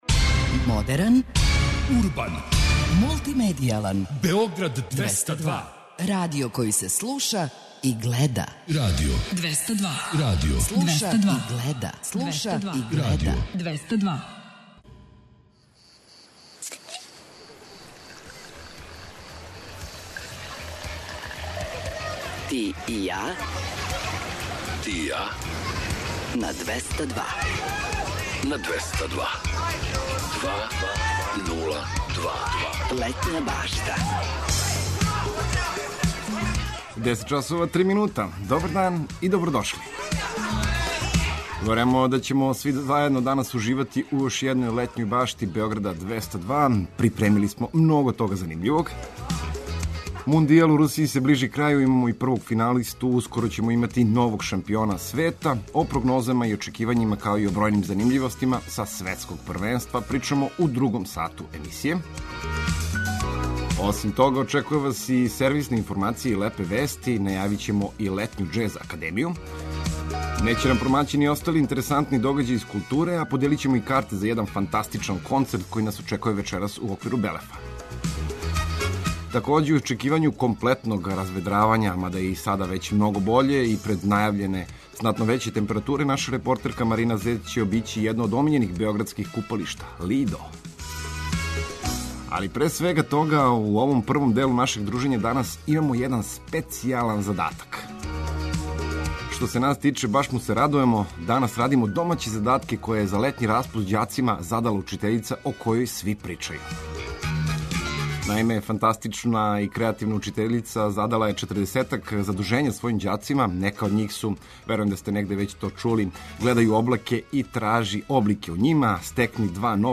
Чућете како су се наши репортери и деца појединих колега снашли у решавању неких од ових „обавеза”.